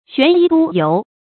玄衣督郵 注音： ㄒㄨㄢˊ ㄧ ㄉㄨ ㄧㄡˊ 讀音讀法： 意思解釋： 督郵：官名。